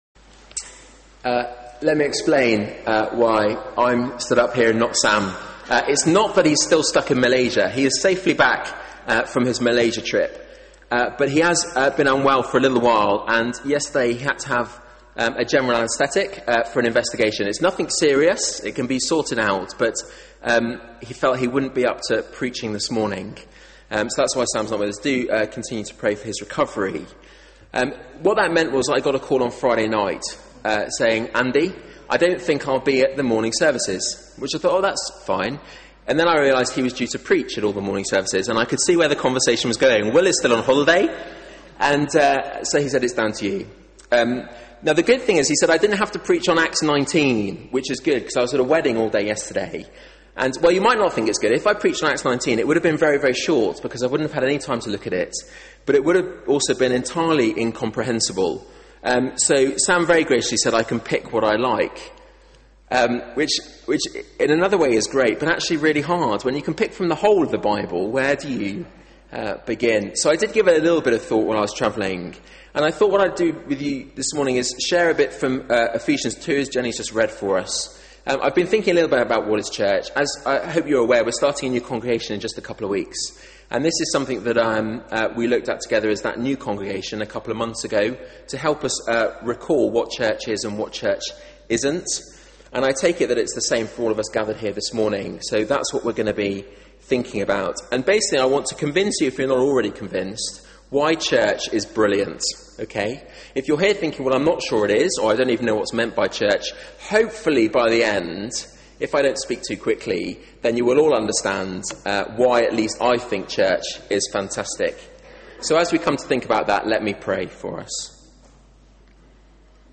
Media for 9:15am Service on Sun 28th Aug 2011 09:15 Speaker
Sermon